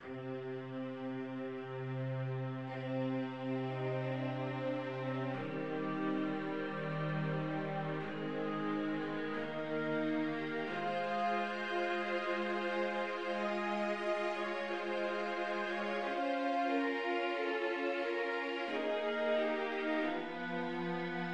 II. Adagio: Sehr feierlich (Very solemnly)
The third theme (bar 53) is characteristic of a funeral march, combining C minor and A major and providing a somber contrast to the preceding love song: